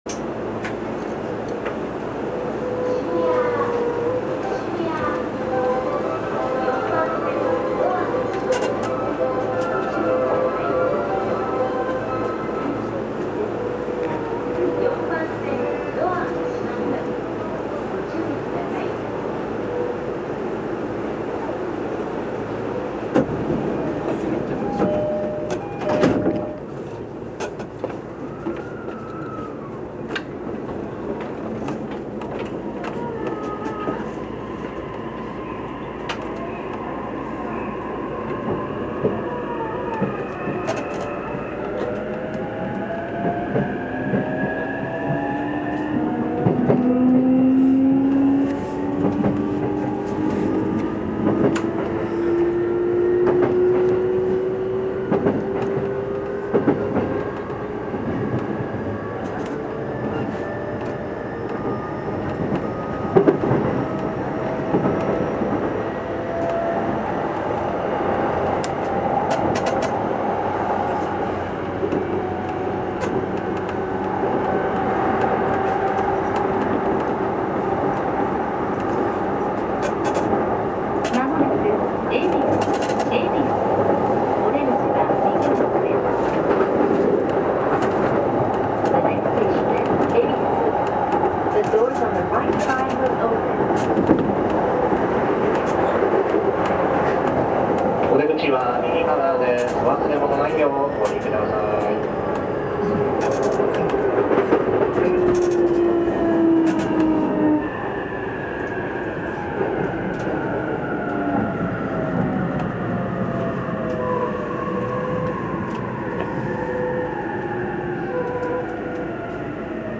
走行音
録音区間：渋谷～恵比寿(お持ち帰り)